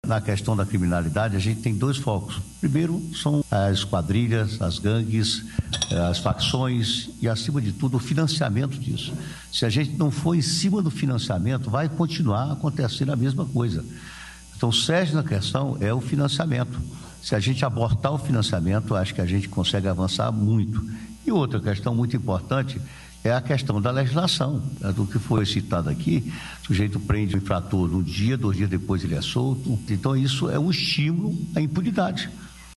(abaixo aperte o play e ouça o governador brandão em brasilia )